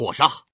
male
fire__slash.mp3